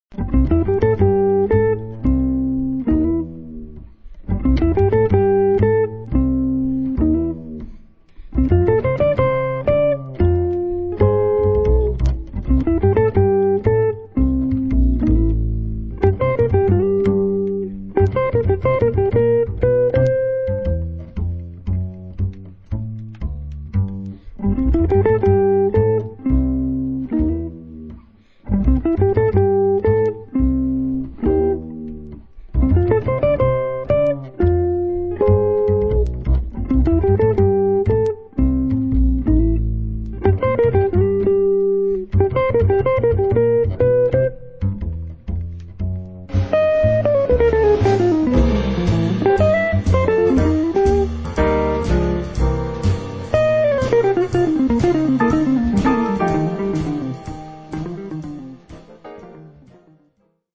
sopra un background ritmico molto sincopato
la registrazione predilige toni molto soft, empatici